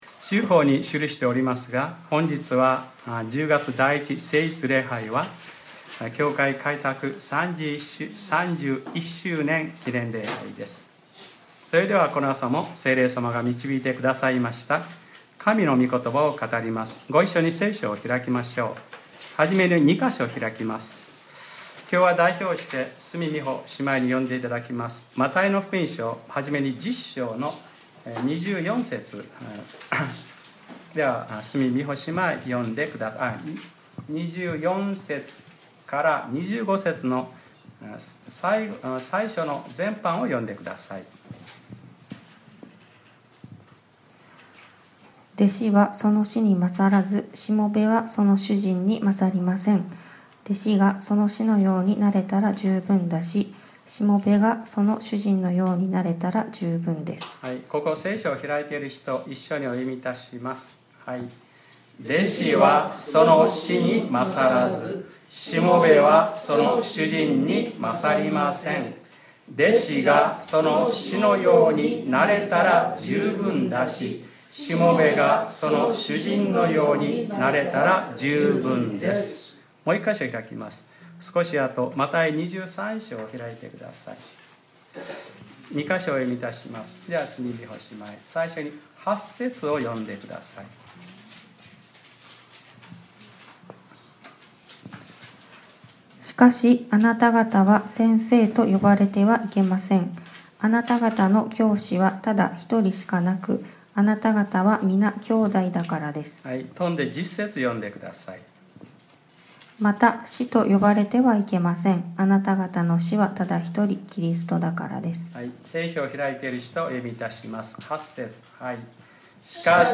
2018年10月07日（日）礼拝説教『弟子が師のようになれたら十分だし』 | クライストチャーチ久留米教会